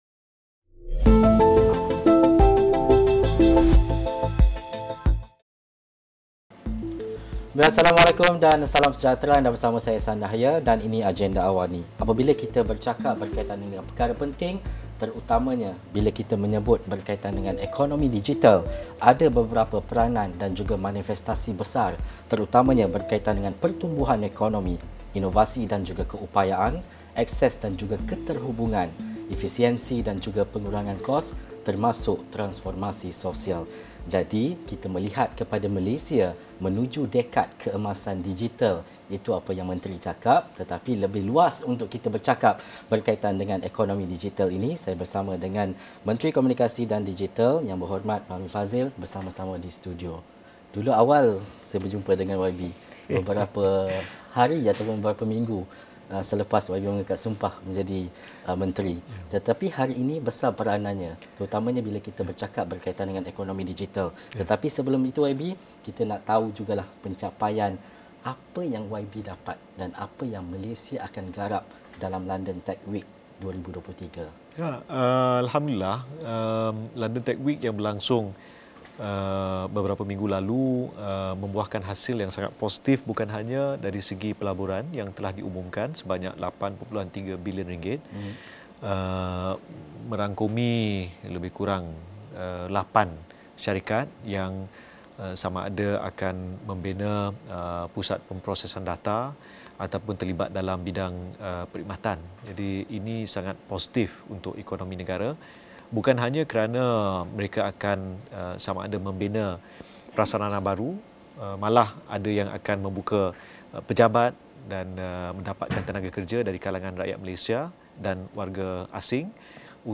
Temu bual khas bersama Menteri Komunikasi dan Digital, Fahmi Fadzil 8.30 malam ini.